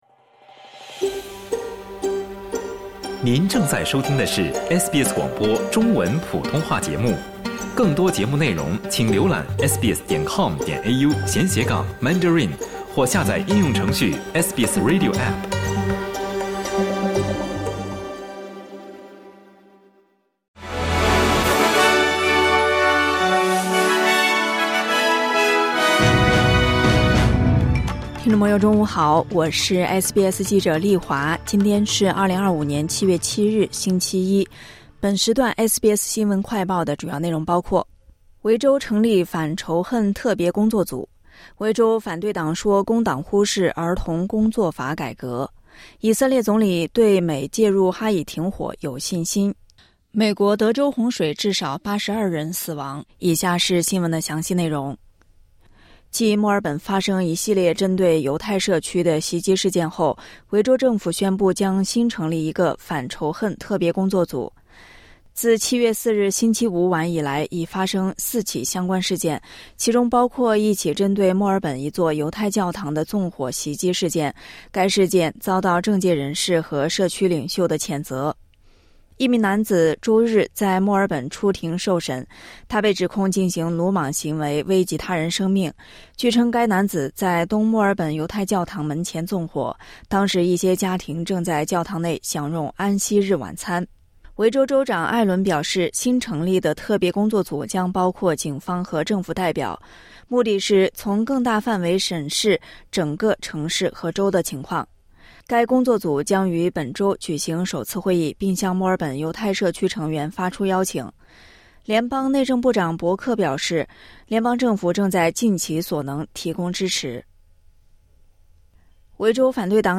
【SBS新闻快报】维州成立反仇恨特别工作组